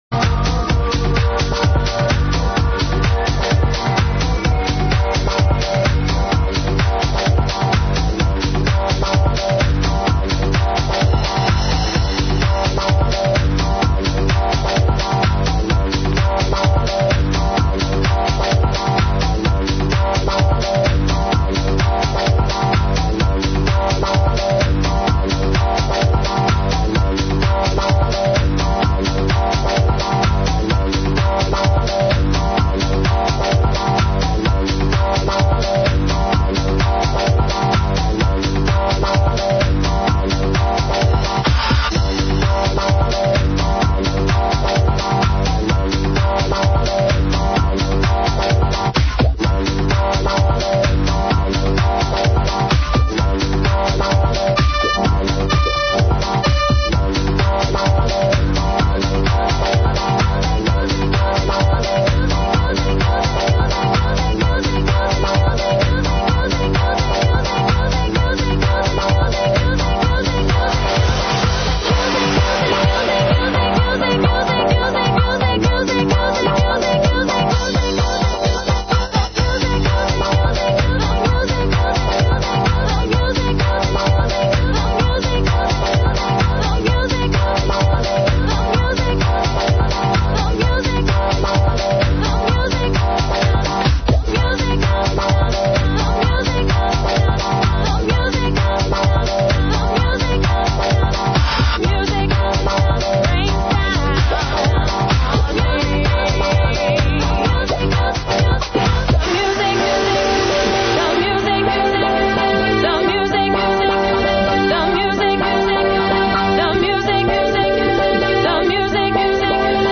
Quand tu les écoutes tu as envie de t'endormir : ils parlent toujours sur le même ton, et ils ne font que de se répéter ! :?
PS : la musique est nulle en plus. :x